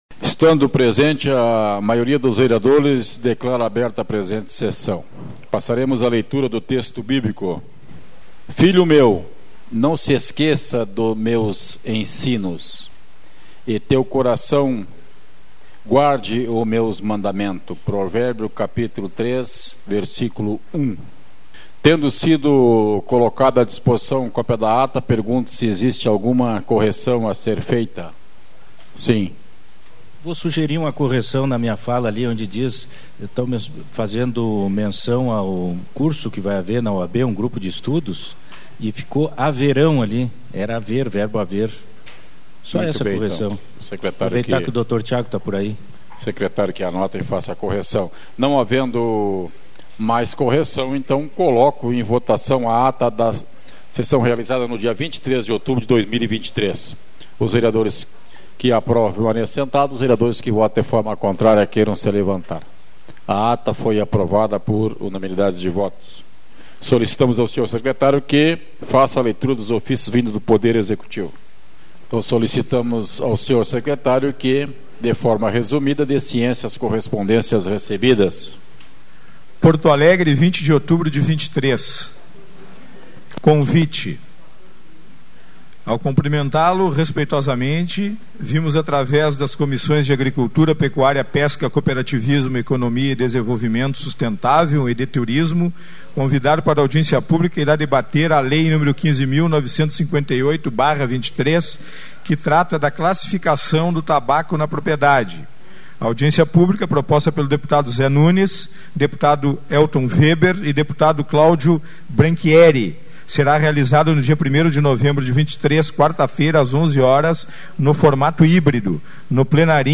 Áudio Sessão 30.10.2023 — Câmara de Vereadores